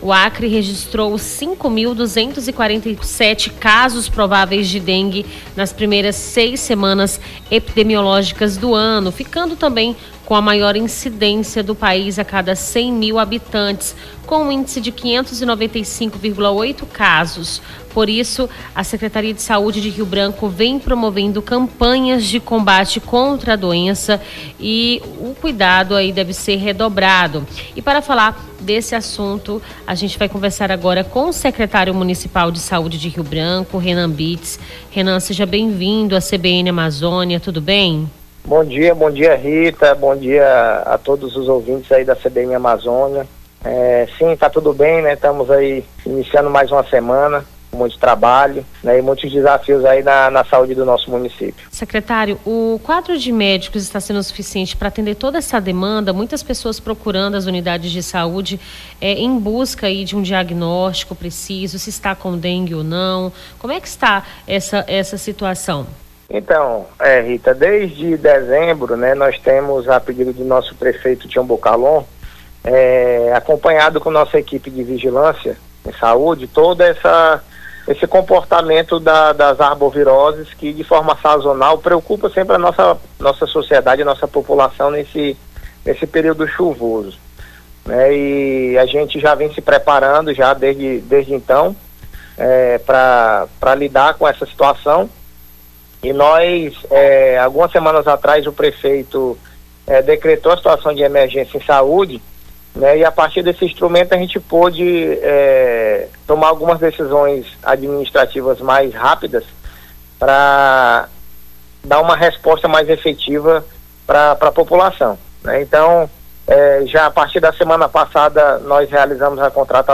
Secretário da saúde de Rio Branco explica quais medidas estão sendo tomadas no combate a dengue
Nome do Artista - CENSURA - ENTREVISTA CUIDADOS DENGUE RIO BRANCO (18-02-25).mp3